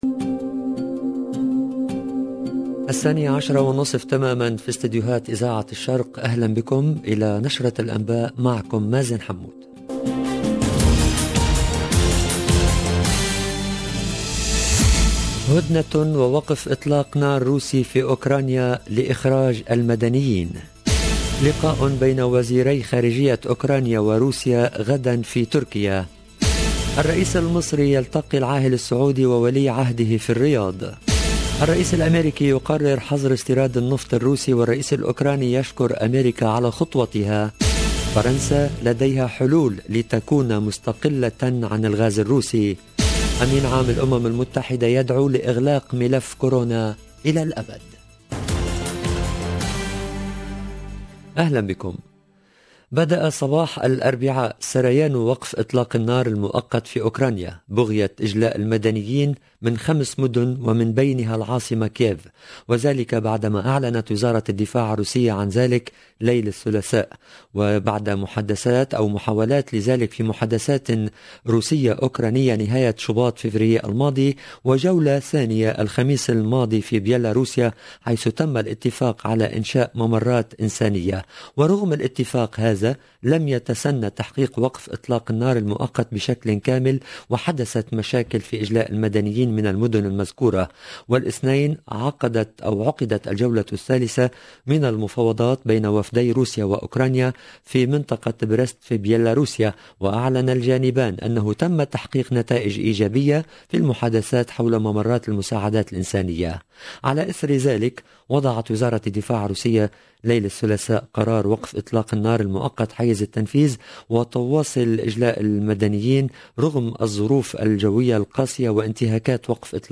LE JOURNAL DE MIDI 30 EN LANGUE ARABE DU 9/03/22